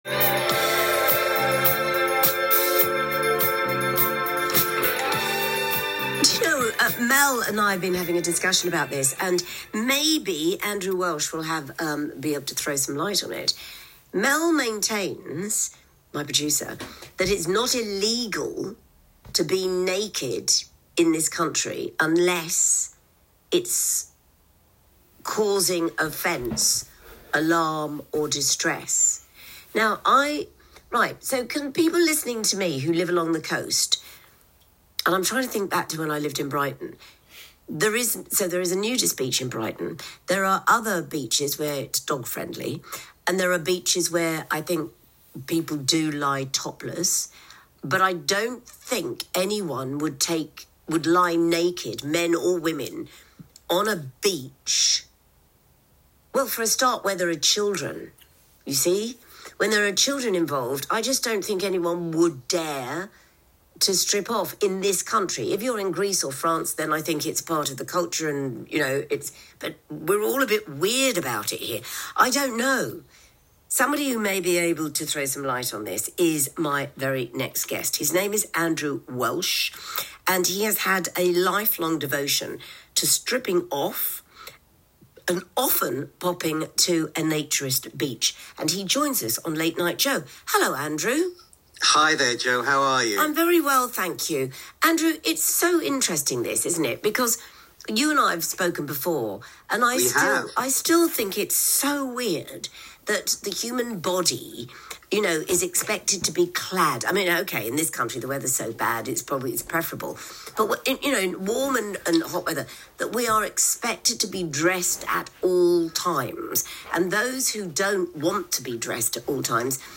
Local Radio interview